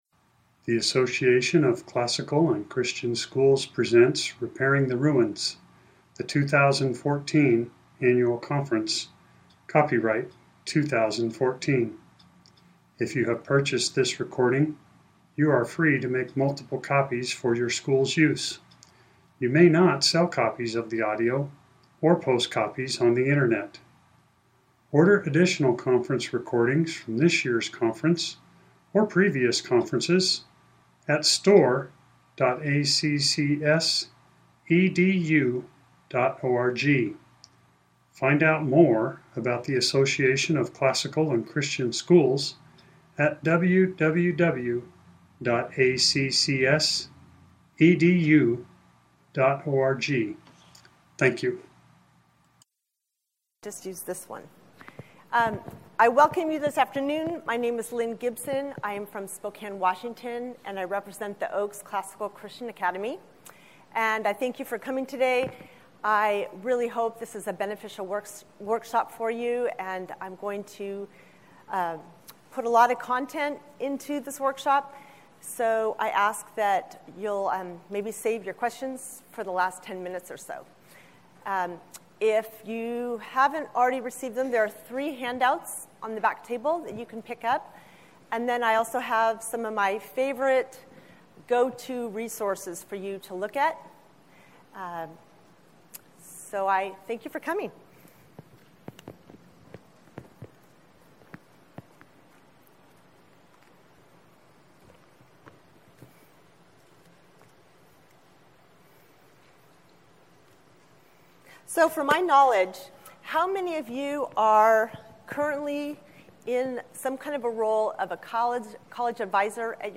2014 Workshop Talk | 1:09:30 | Leadership & Strategic